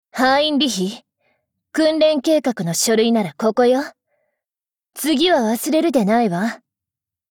[[Category:碧蓝航线:彼得·史特拉塞语音]]